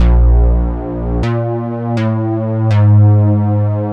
Ox Bass_122_Bb.wav